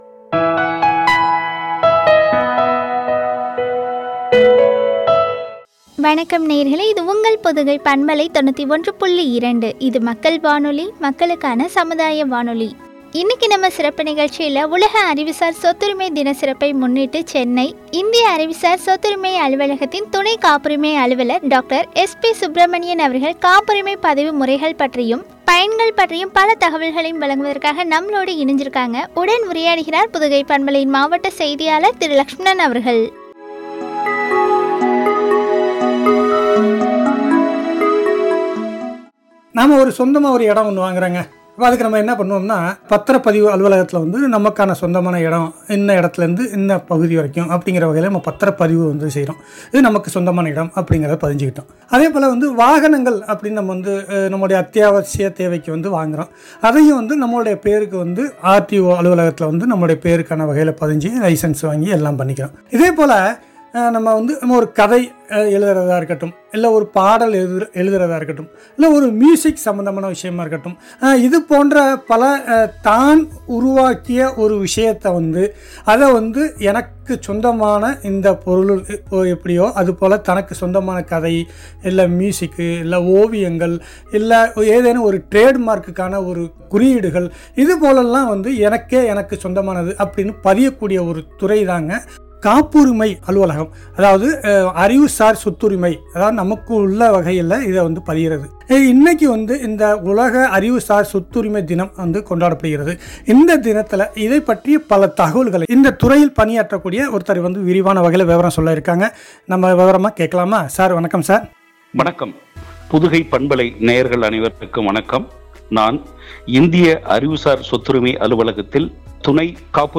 பயன்களும் பற்றிய உரையாடல்.